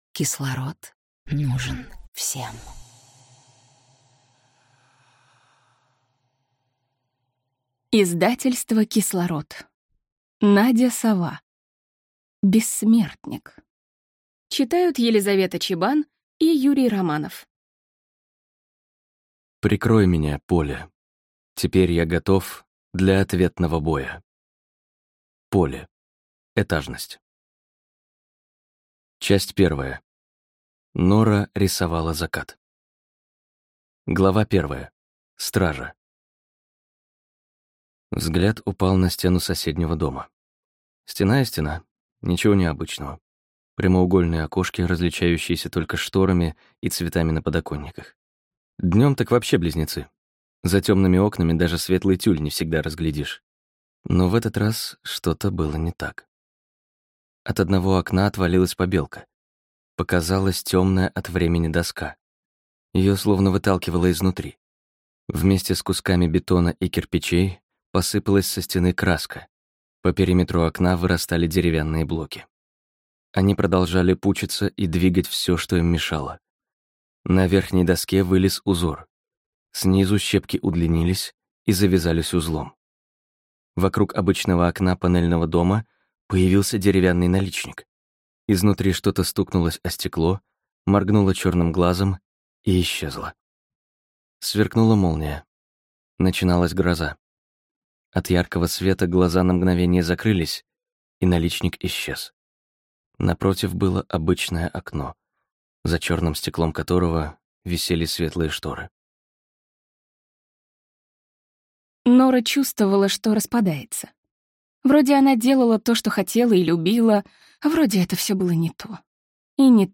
Aудиокнига Бессмертник